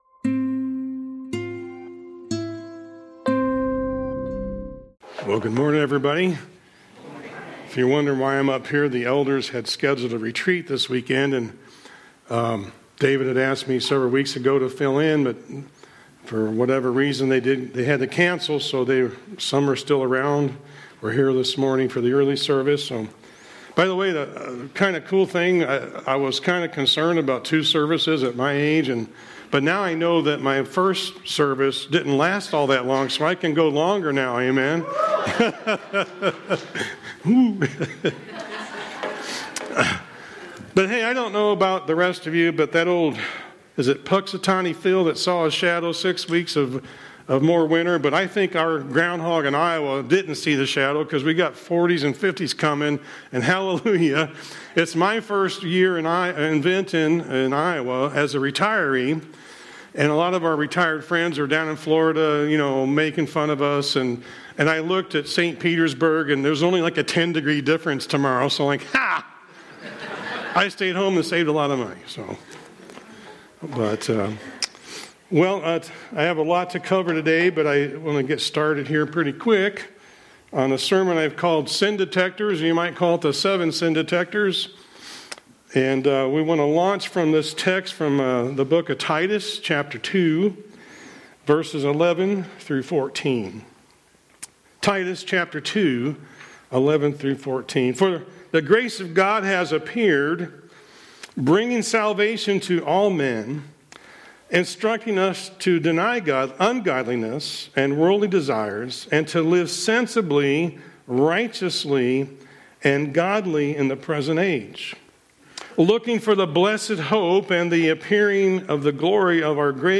Guest Speaker – Sin Detectors | Blessed Hope Community Church
Feb-8-26-Sermon-Audio.mp3